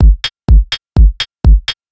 Сэмплы ударных (Дэнс Электро): One Way D
Тут вы можете прослушать онлайн и скачать бесплатно аудио запись из категории «Dance Electro № 2».